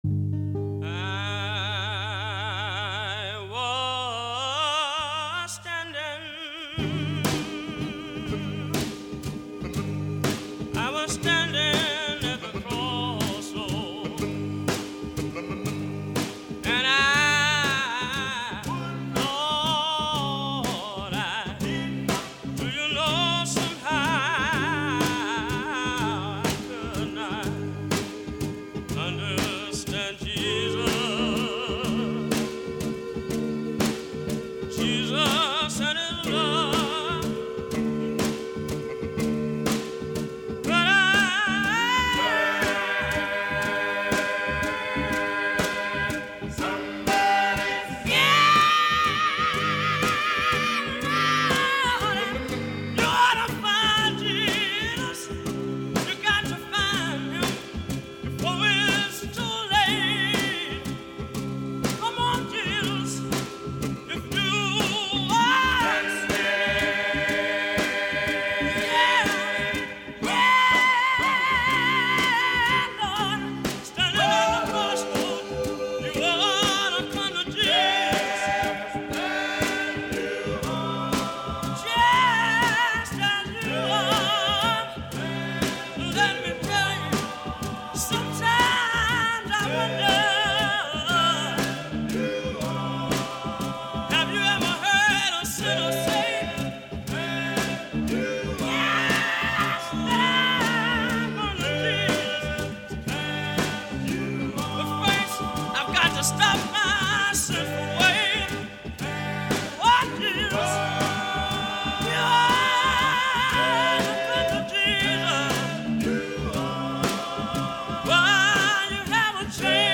Scratchy vanity 45s, pilfered field recordings, muddy off-the-radio sounds, homemade congregational tapes and vintage commercial gospel throw-downs; a little preachin', a little salvation, a little audio tomfoolery.